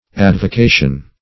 Advocation - definition of Advocation - synonyms, pronunciation, spelling from Free Dictionary
Advocation \Ad`vo*ca"tion\, n. [L. advocatio: cf. OF. avocation.